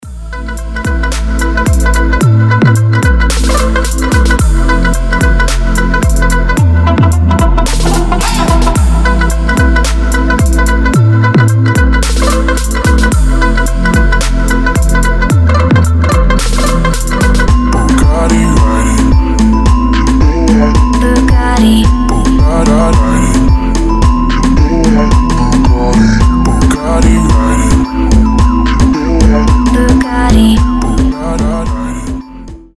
• Качество: 320, Stereo
deep house
мелодичные
басы
восточные
качающие